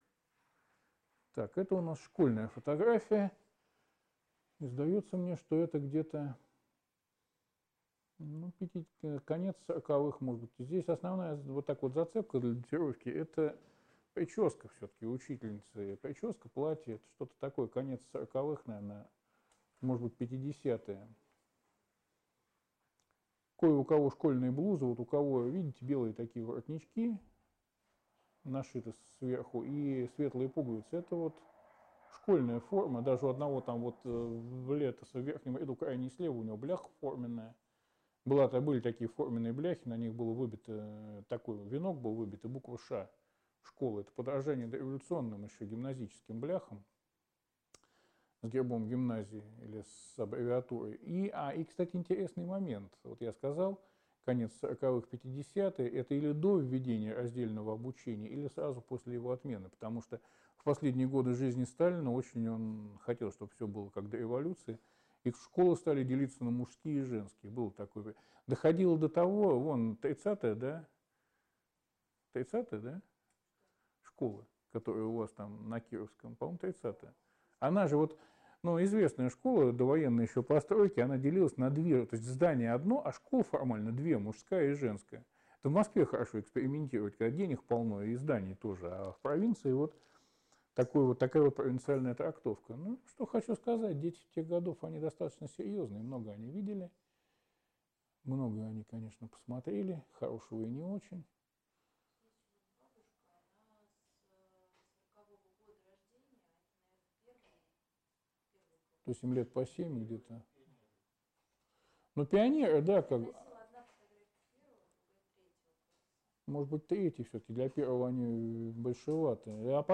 3. «Сеансы связи» в Доме Белявского